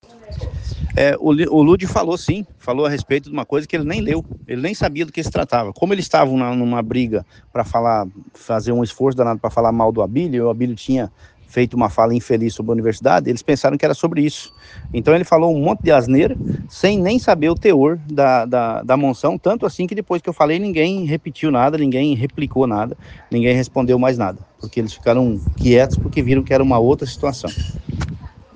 ESCUTE OS ÁUDIOS DA ENTREVISTA DADA PELO DEPUTADO GILBERTO CATTANI AO OPINIÃO MT